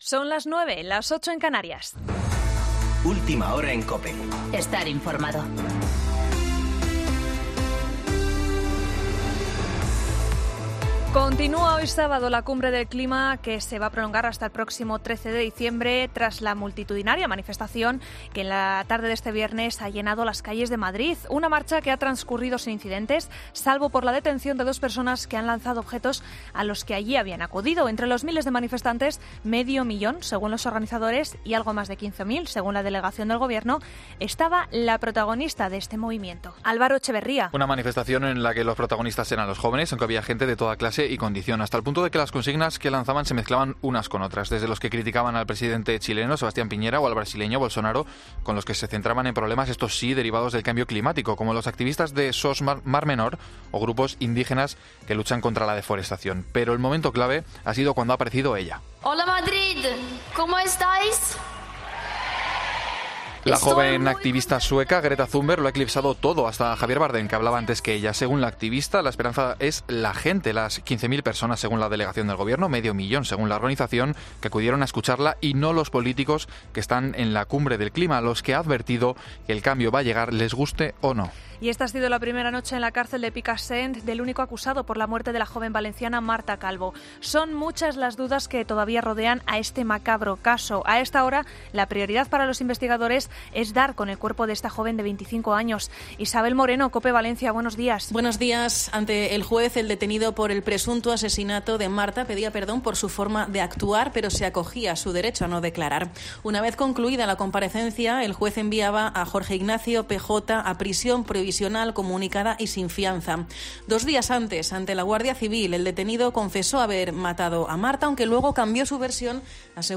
Boletín de noticias COPE del 7 de diciembre de 2019 a las 09.00 horas